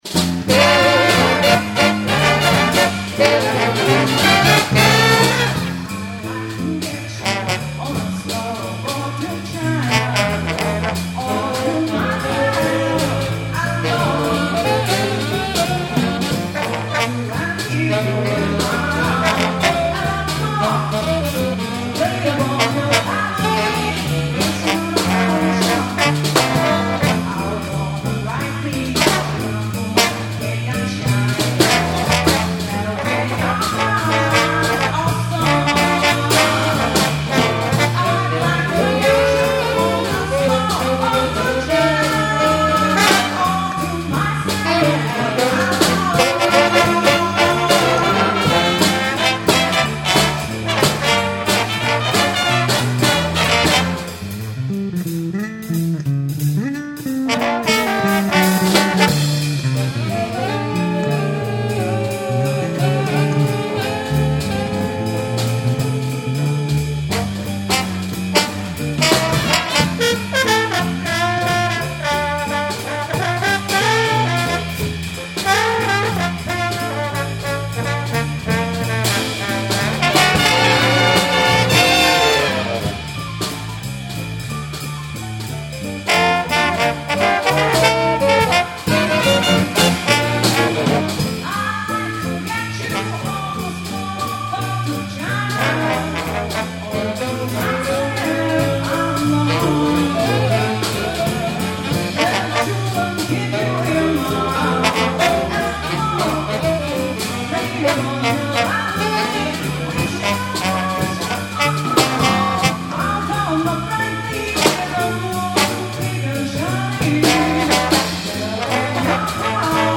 2008-03-16 桜井サクライ市民シミン会館カイカン ギンオンじゃず楽団ガクダン
(ダイ26カイ公民コウミンカンマツ)